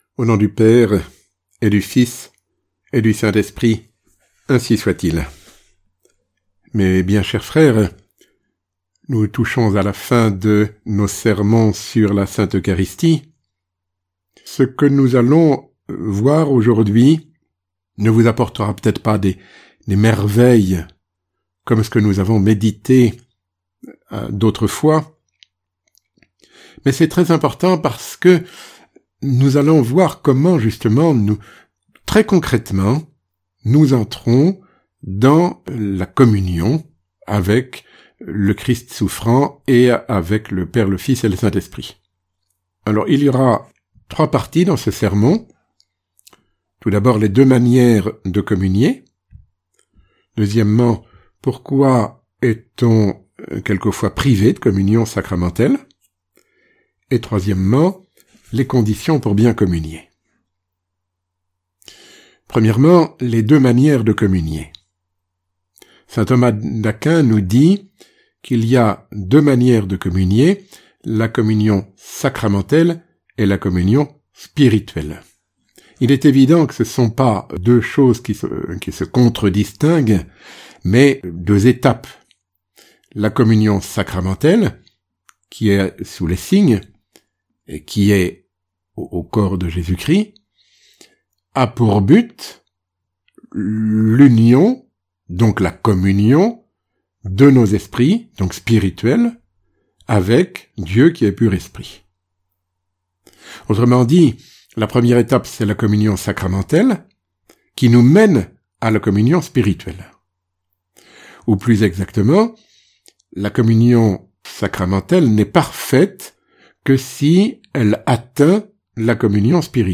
Sermon ~ L’Eucharistie, la présence réelle du Christ immolé